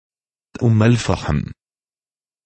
Umm_al-Fahm_in_Arabic.ogg.mp3